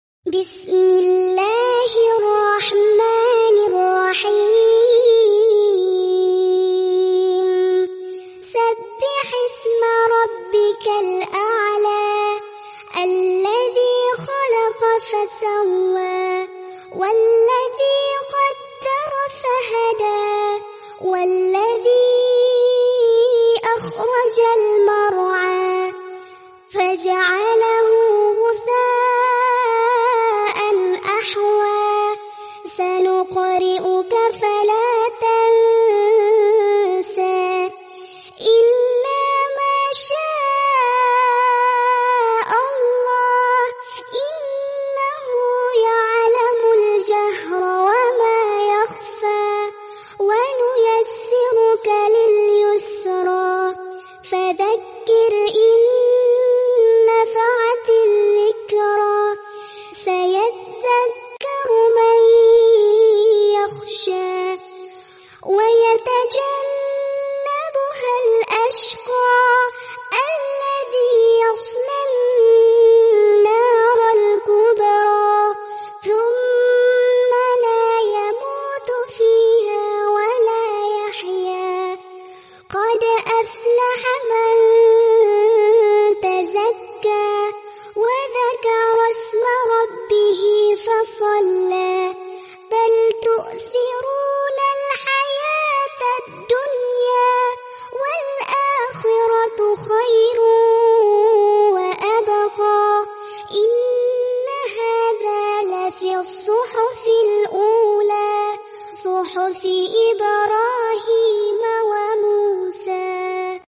QS 87 AL 'ALA Bayi Ngaji Murottal Juz 30 Bacaan Anak (Video/gambar fiktif dibuat oleh AI menggunakan HP android).